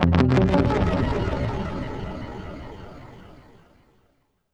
GUITARFX18-L.wav